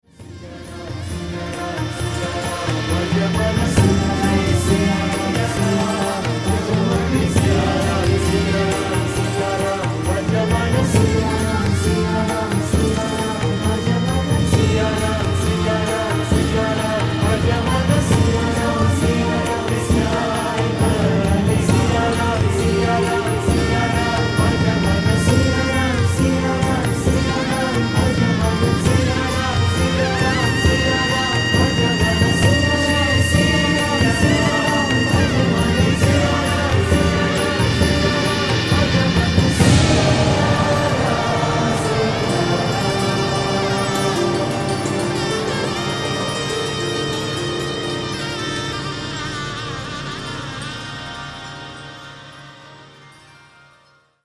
recorded in high definition 24 bit digital sound in the UK
bansuri
sarod
santoor
sitar